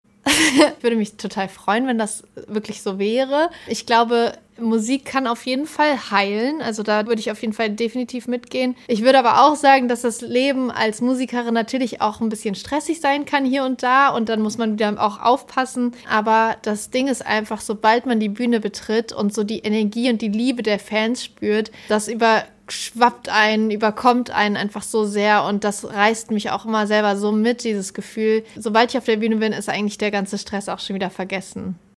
Sängerin Lea